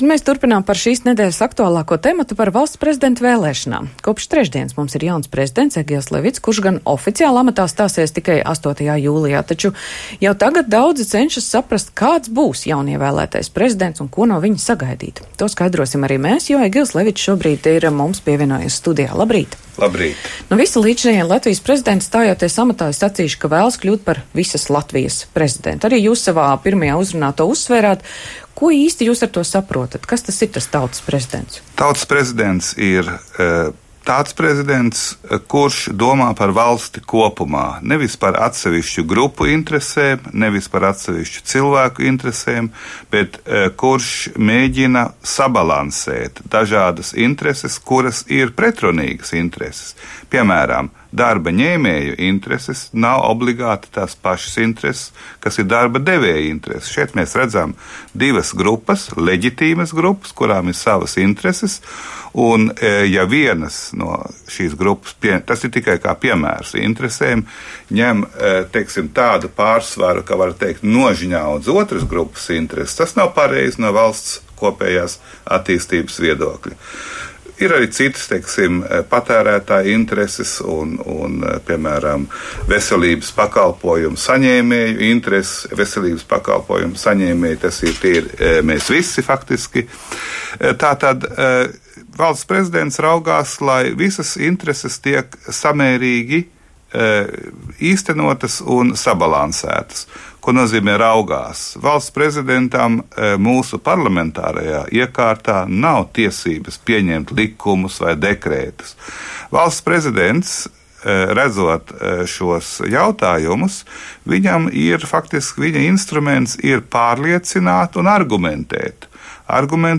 Studijā šonedēļ Valsts prezidenta amatā ievēlētais Egils Levits.